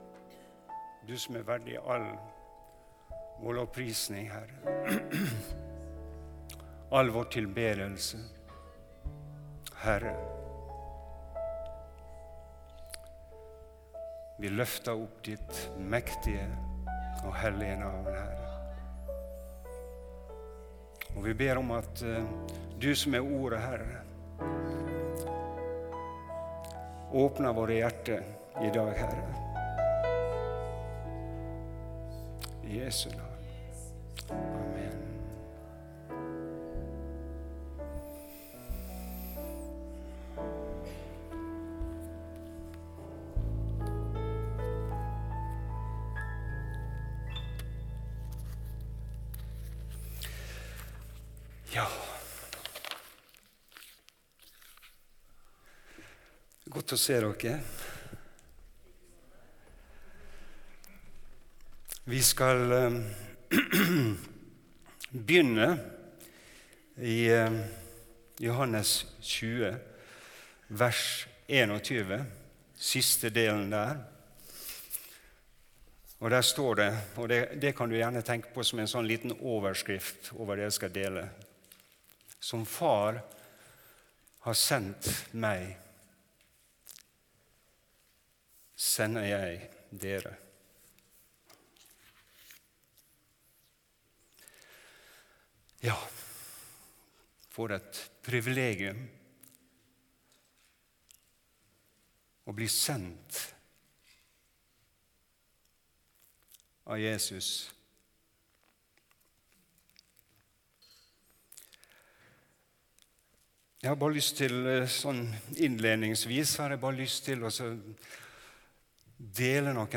Tale